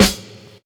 Snares
UY_SNR`.wav